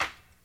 TC Clap Perc 06.wav